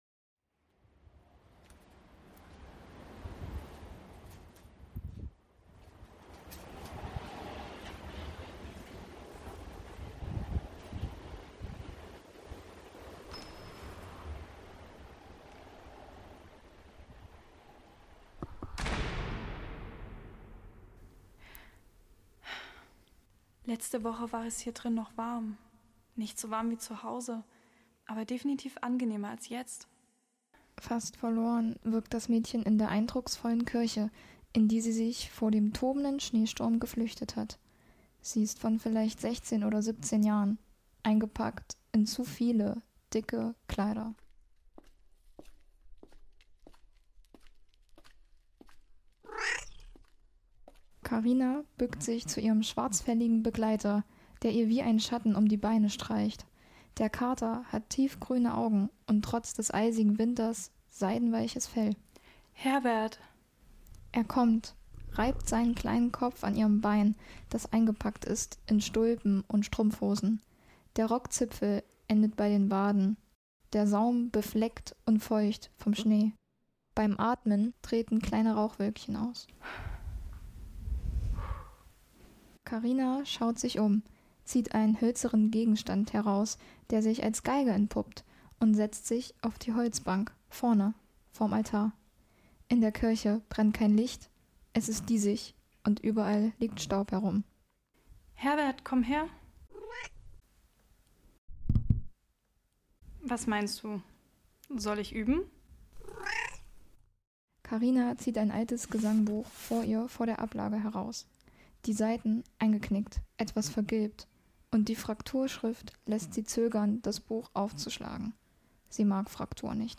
Studentisches Hörspiel: "Unser Lied für später"